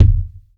impact_deep_thud_bounce_02.wav